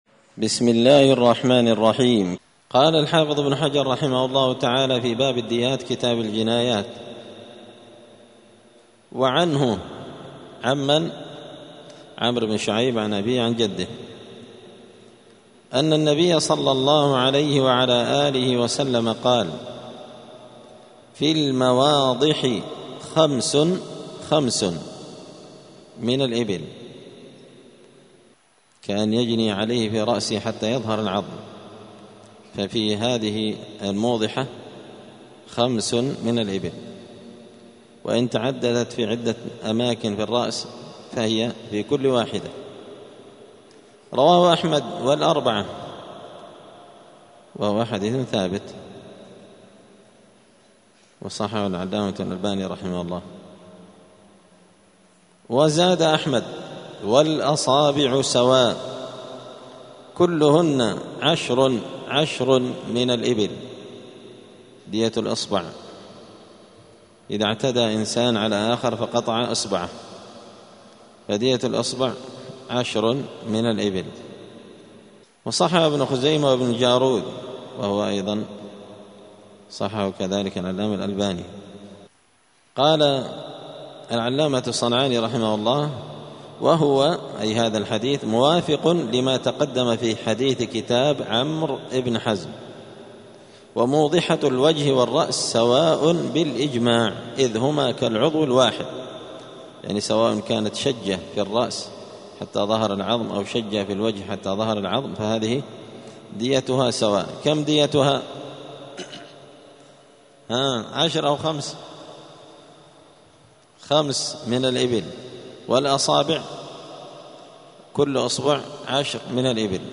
*الدرس الرابع والعشرون (24) {باب الديات في المواضح خمس من الإبل}*